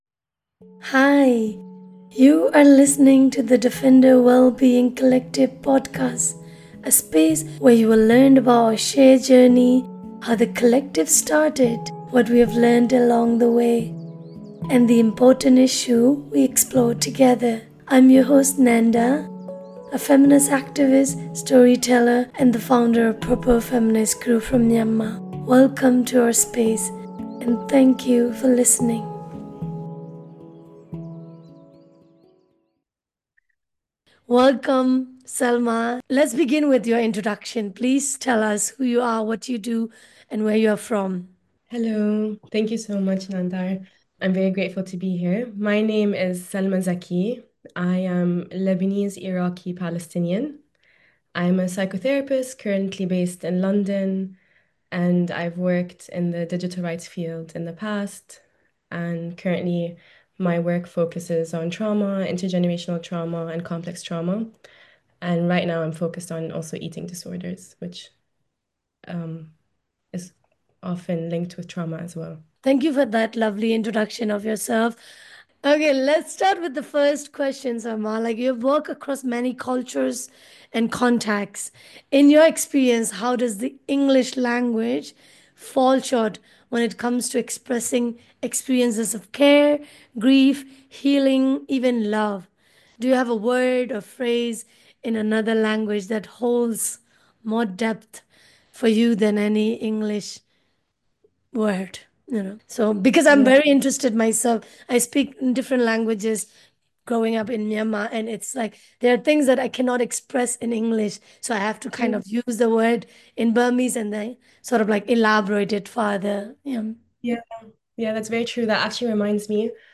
Episode 2 is a conversation about how our native language(s) tell more stories and brings emotional layers of our lived experiences that English simplifies.